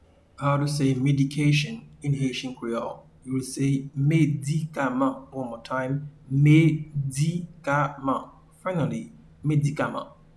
Pronunciation :
Medication-in-Haitian-Creole-Medikaman.mp3